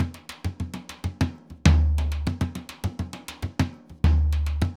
Surdo Salsa 100_2.wav